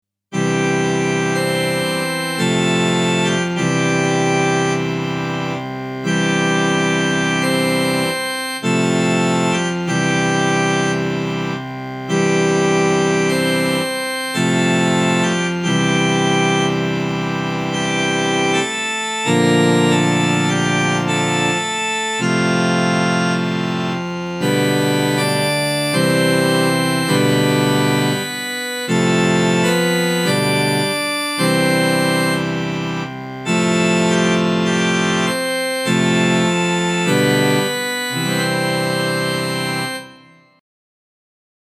INSTRUMENTAL
Grabaciones hechas en la Ermita durante la celebración de las Novenas del año 2019/2022.